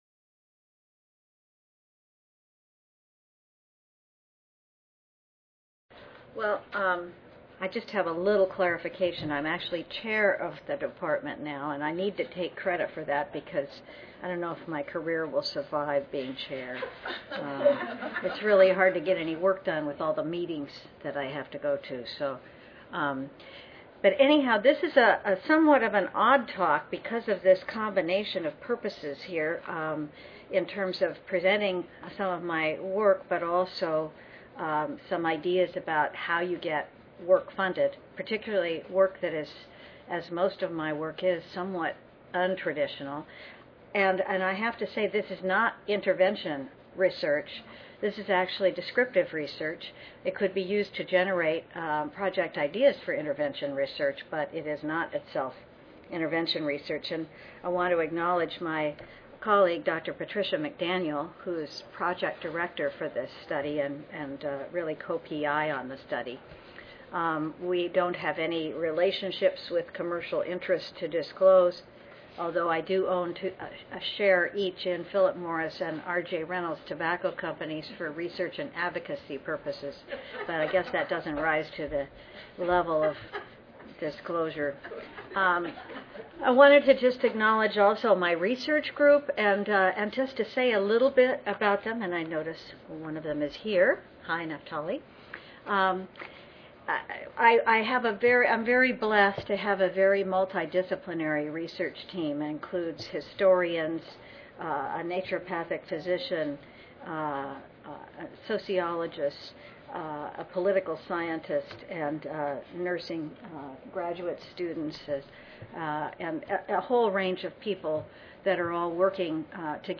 3067.0 Building Successful Programs of Public Health Nursing Research: Funded Exemplars across Levels of Intervention and Stages of Development Monday, October 29, 2012: 8:30 AM - 10:00 AM Oral This symposium includes the presentation of findings from two nationally recognized nurse researchers whose work has been consistently funded by the National Institutes of Health to address health issues relevant to guiding public health nursing practice.